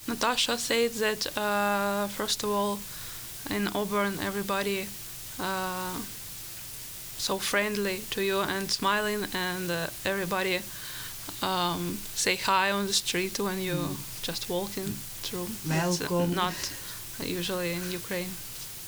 Despite the culture shock and language barrier, the Ukrainians who spoke with Finger Lakes Daily News said Auburn has been a welcoming place.